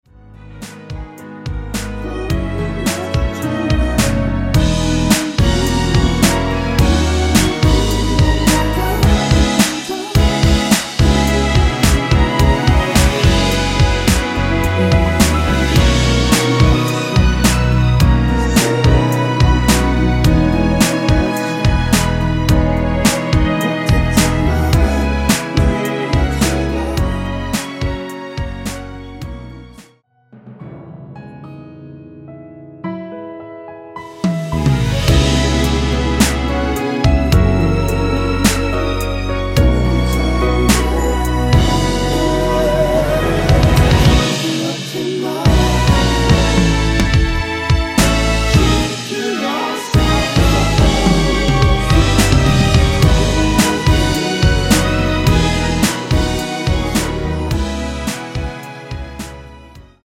원키에서(-1)내린 코러스 포함된 MR입니다.
◈ 곡명 옆 (-1)은 반음 내림, (+1)은 반음 올림 입니다.
앞부분30초, 뒷부분30초씩 편집해서 올려 드리고 있습니다.